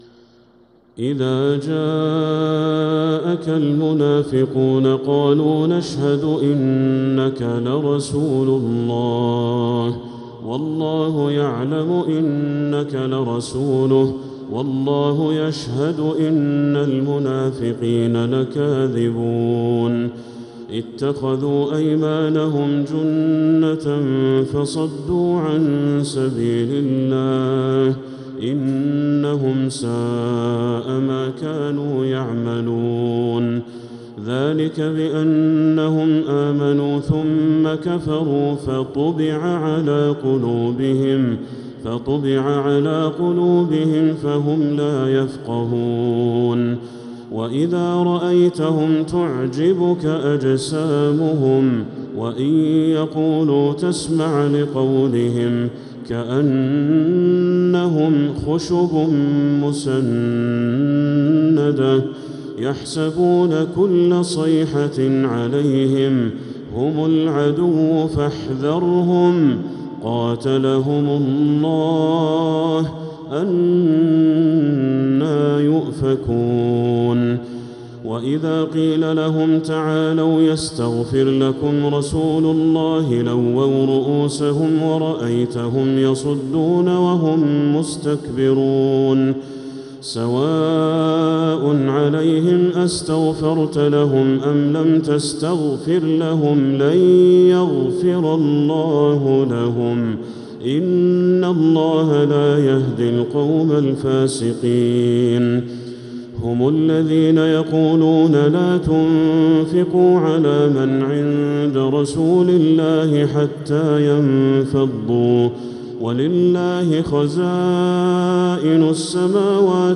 سورة المنافقون| مصحف تراويح الحرم المكي عام 1446هـ > مصحف تراويح الحرم المكي عام 1446هـ > المصحف - تلاوات الحرمين